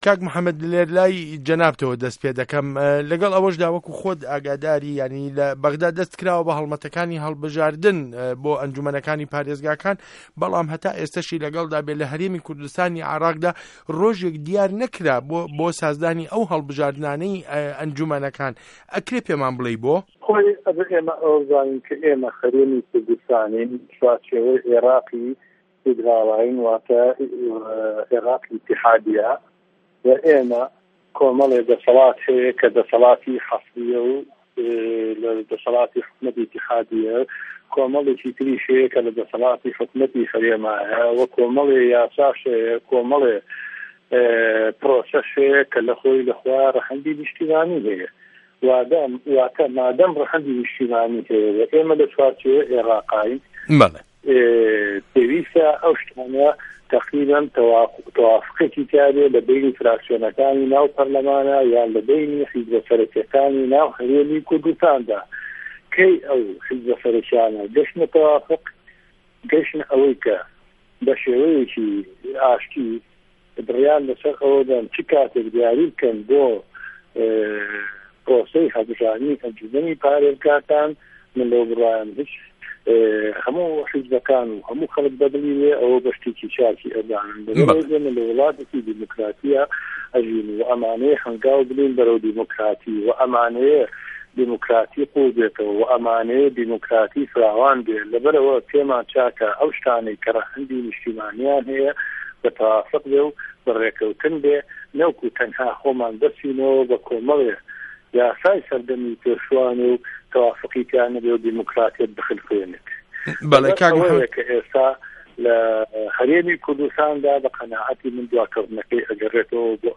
مێزگرد له‌سه‌ر هه‌ڵبژاردنی ئه‌نجومه‌نی پارێزگاکان